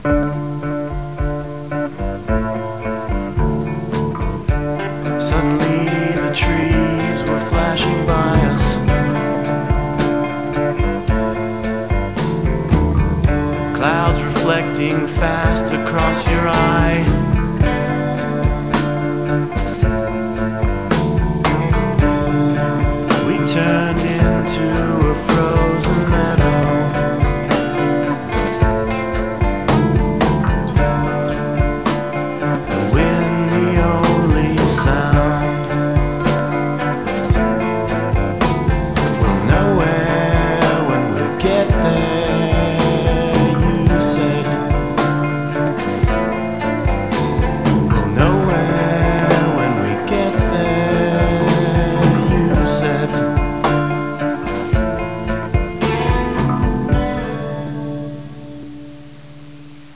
guitar / vocals